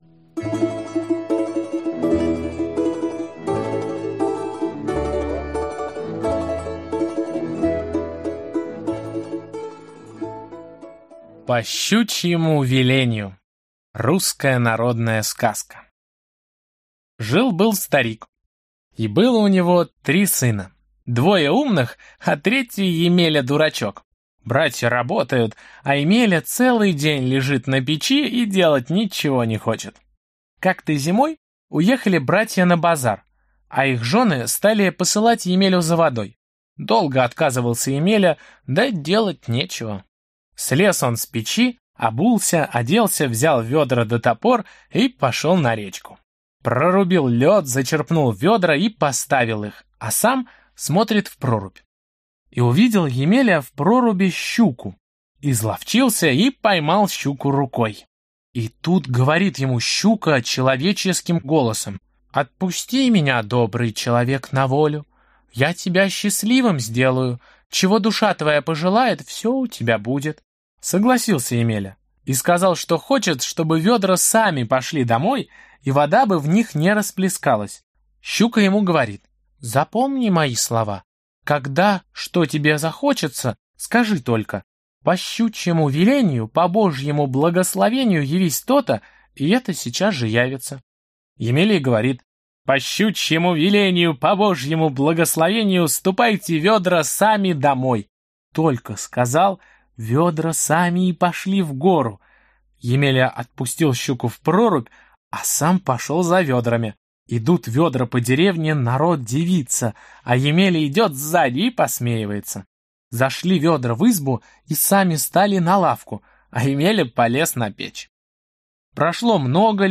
Аудиокнига По щучьему велению | Библиотека аудиокниг